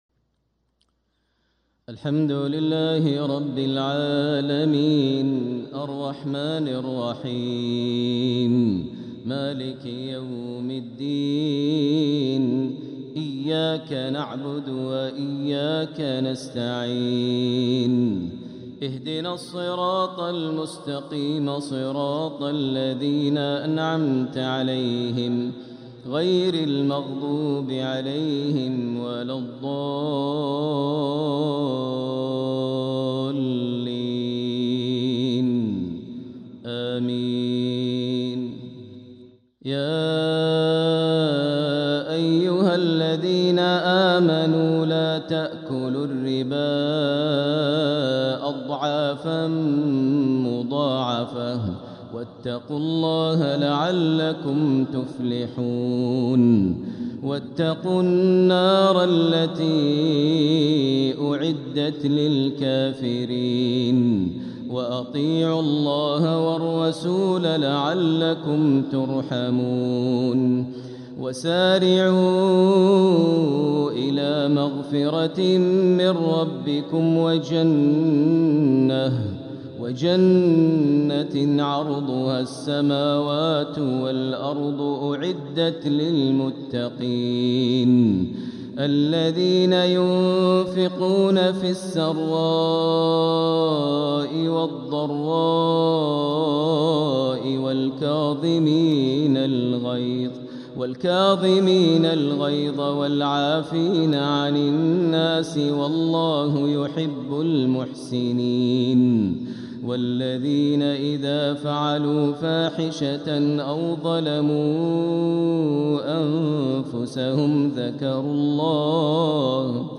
النور التام لفروض المسجد الحرام 🕋 من 11 جمادى الأولى إلى 17 جمادى الأولى 1447هـ ( الحلقة 82) > إصدارات النور التام لفروض المسجد الحرام 🕋 > الإصدارات الشهرية لتلاوات الحرم المكي 🕋 ( مميز ) > المزيد - تلاوات الحرمين